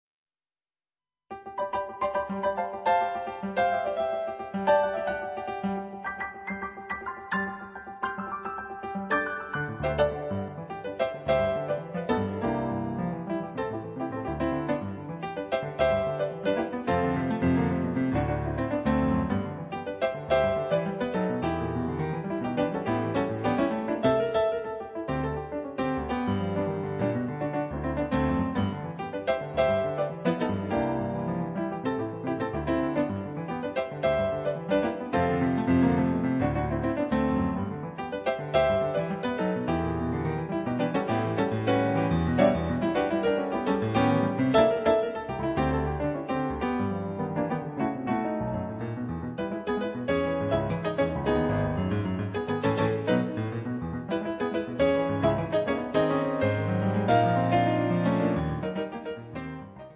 pianoforte
dal suono avvolgente e trascinante.